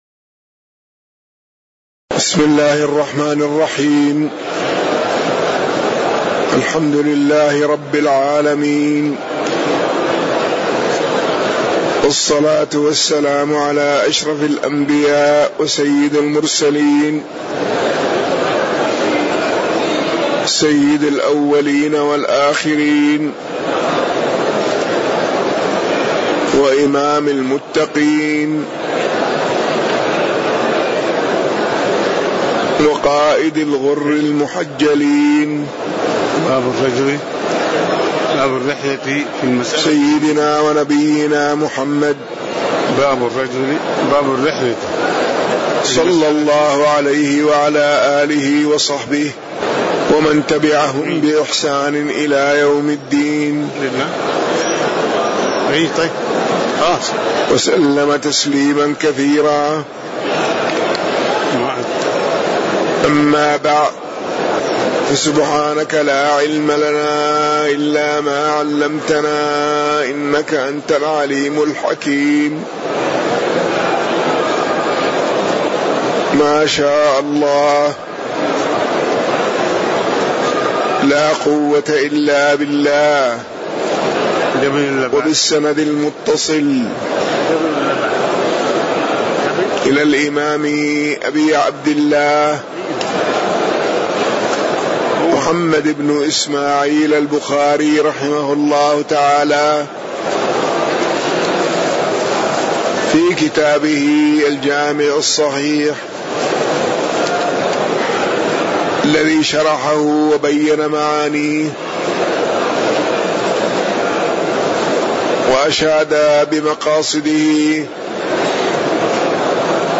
تاريخ النشر ٧ شعبان ١٤٣٩ هـ المكان: المسجد النبوي الشيخ